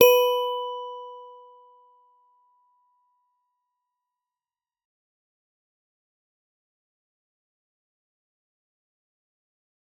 G_Musicbox-B4-f.wav